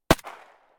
ordinaryBullet.ogg